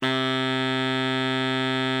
bari_sax_048.wav